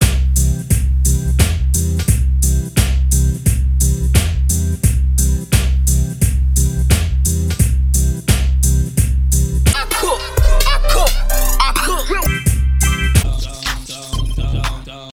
Old School Hip-Hop